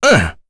Kain-Vox_Damage_01.wav